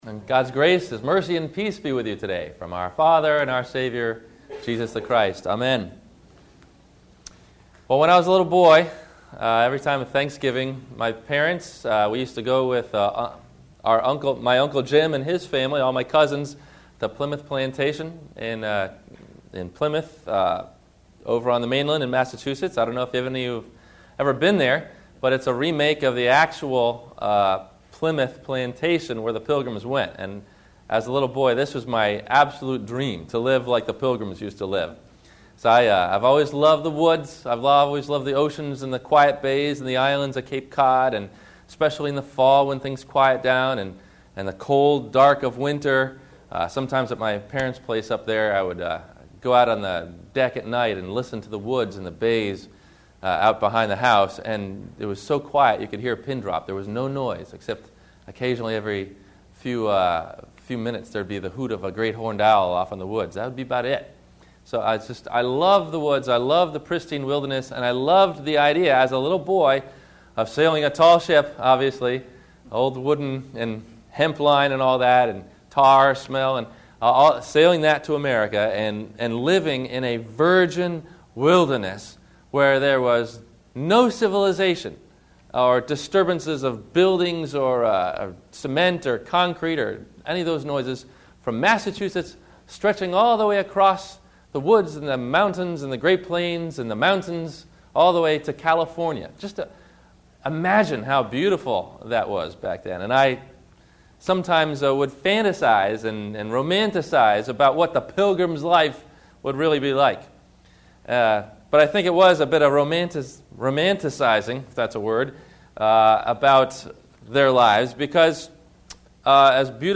The Deep Wilderness – Thanksgiving Day – Sermon – November 27 2008